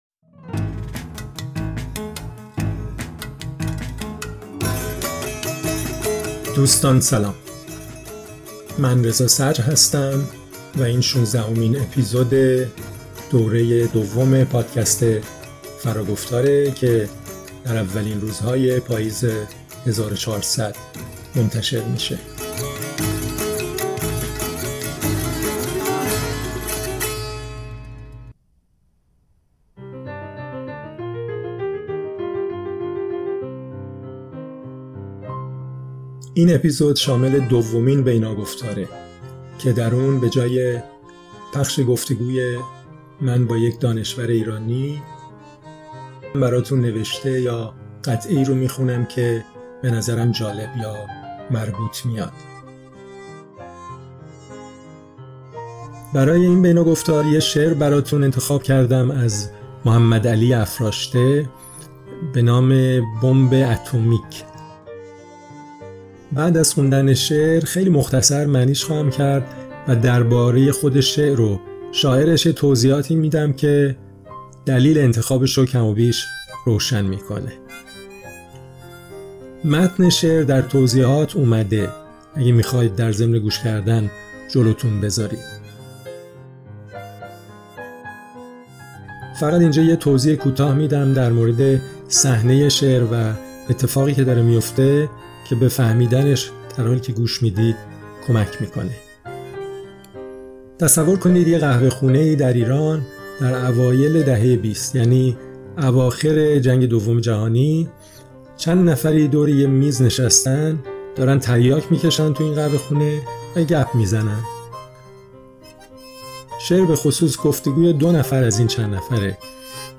خواندن و توضیح شعر طنز زیبایی محمدعلی افراشته با مختصری از شرح حال او